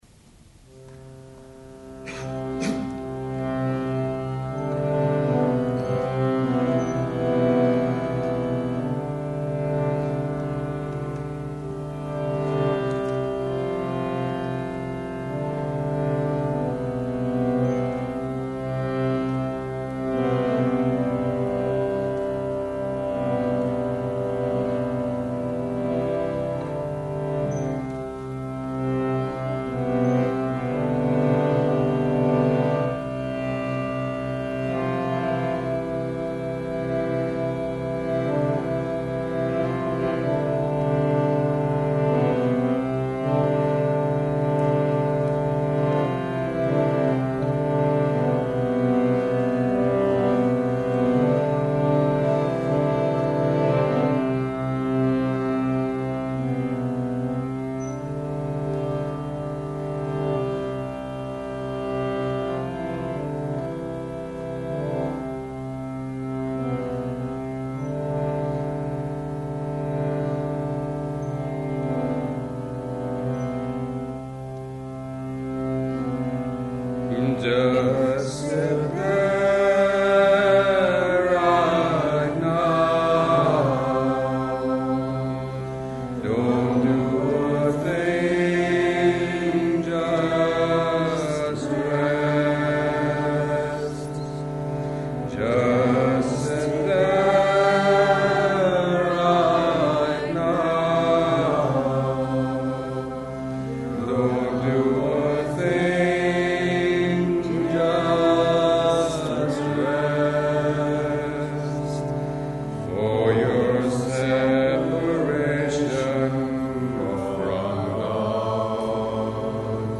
Music from The Tiltenberg (may 4/5 2001)